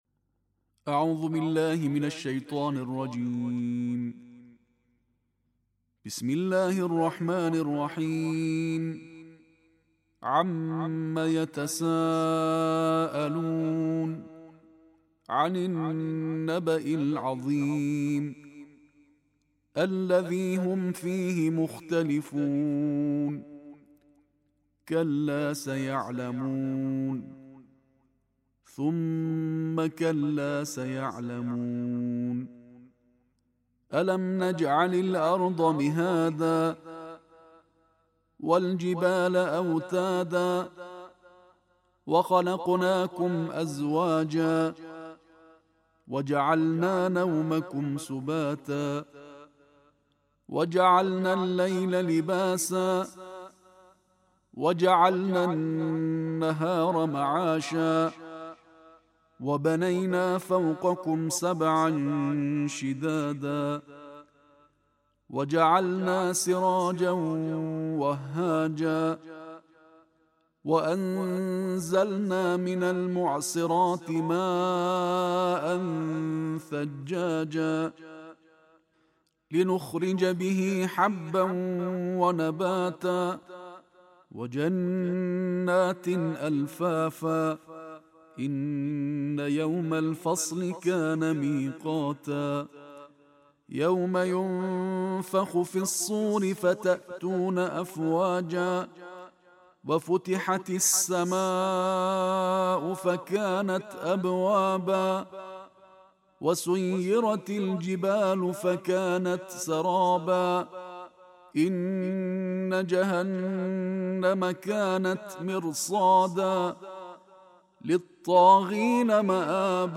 Каждый день с Кораном: Тартиль тридцатого джуза Корана